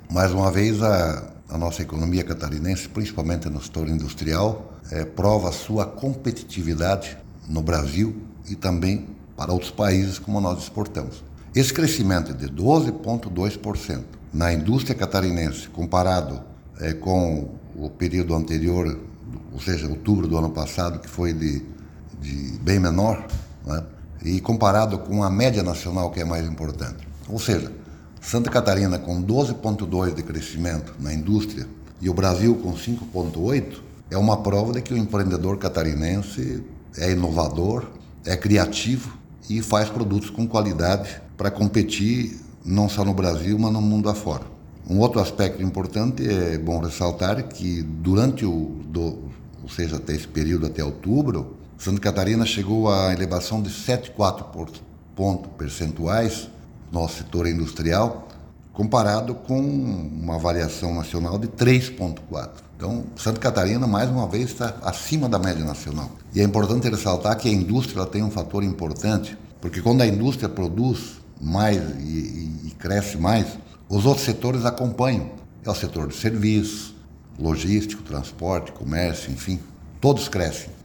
O secretário de Estado de Indústria, Comércio e Serviço, Silvio Dreveck, analisa o resultado:
SECOM-Sonora-Secretario-Dreveck-Crescimento-Industrial.mp3